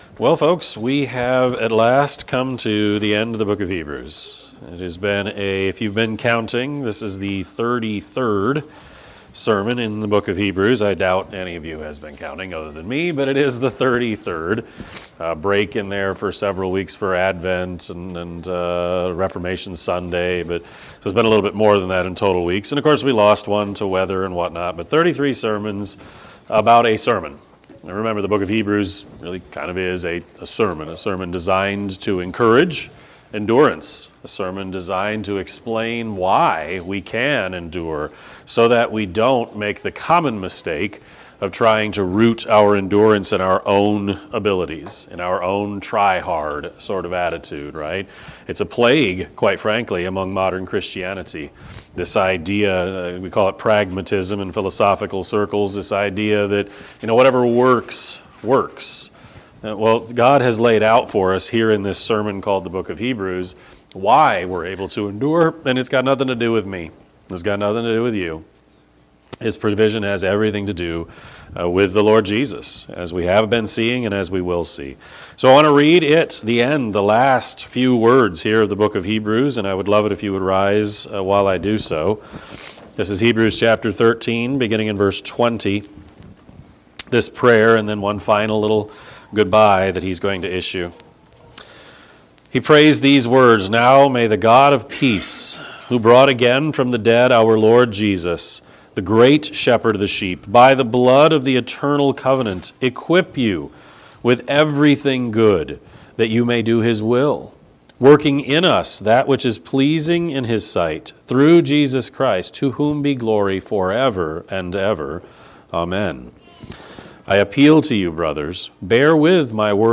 Sermon-2-22-26-Edit.mp3